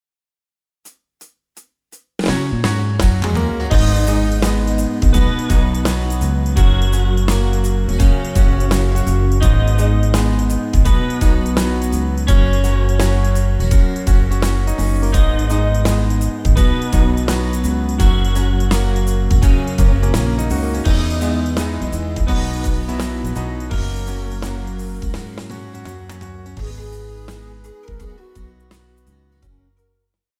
Žánr: Rock
BPM: 168
Key: G#m
MP3 ukázka s ML